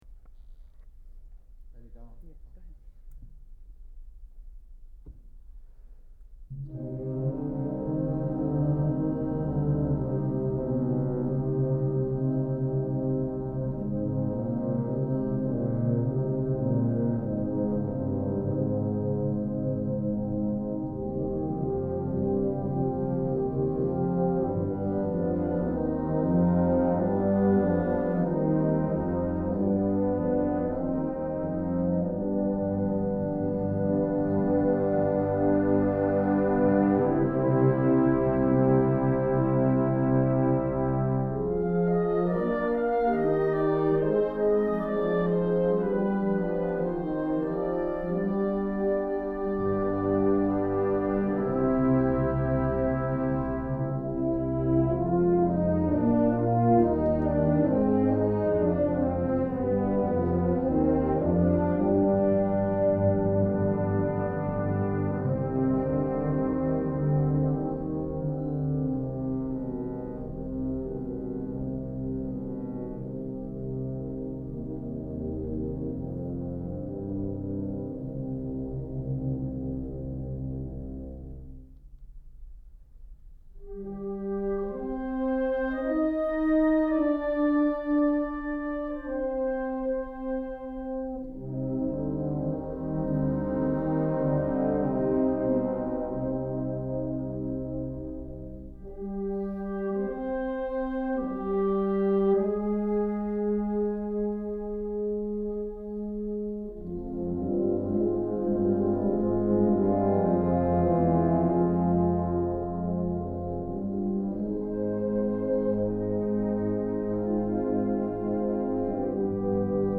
Genre: Band
Flute I/II
Oboe I/II
Bassoon
Bass Clarinet
Tenor Saxophone
Bb Trumpet I/II
Horn in F I/II
Euphonium
Tuba
Timpani